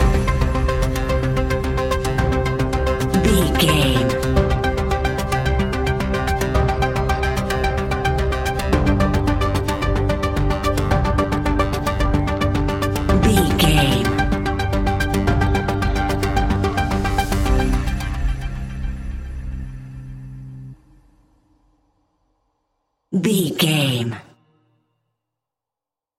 In-crescendo
Thriller
Aeolian/Minor
ominous
eerie
Horror Pads
horror piano
Horror Synths